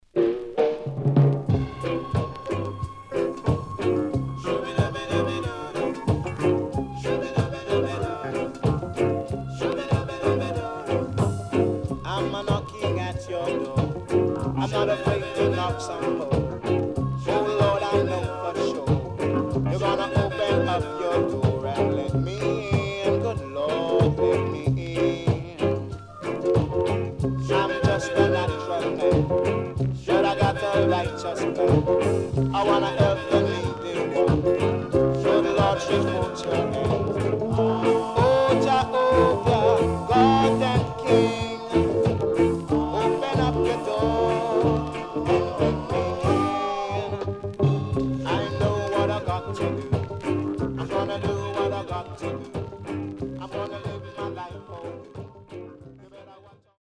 SIDE A:プレス起因により所々ノイズ入ります。